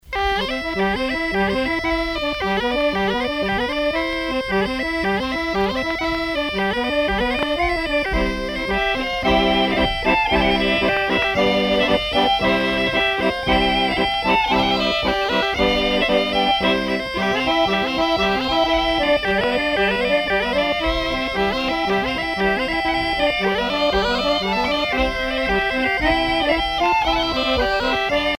danse : gigouillette
Pièce musicale éditée